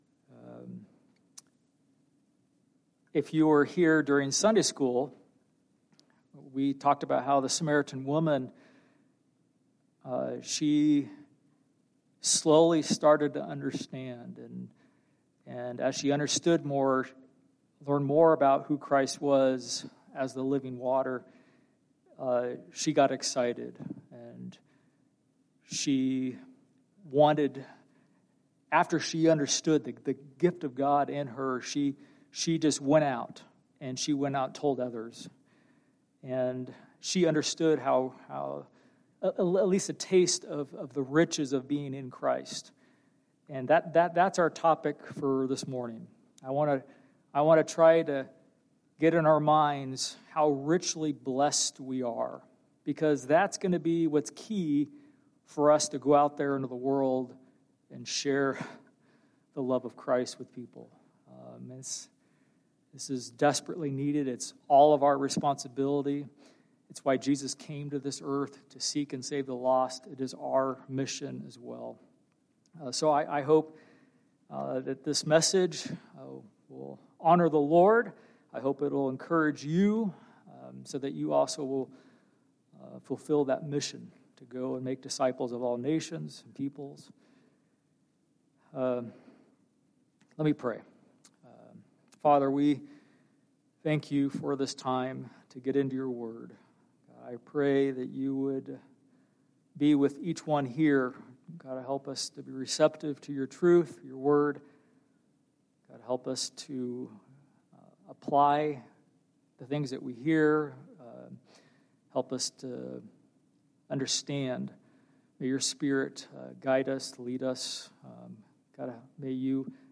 Past Sermons - Kuna Baptist Church
From Series: "Guest Preacher"